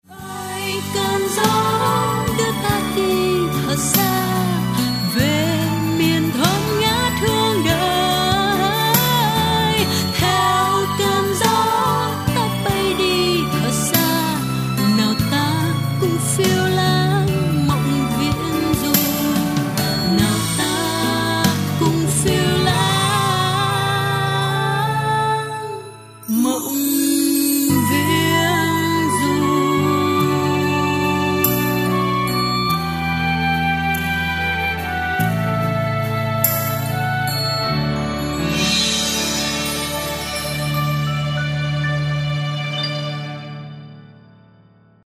Pop/ Acoustic/ Indie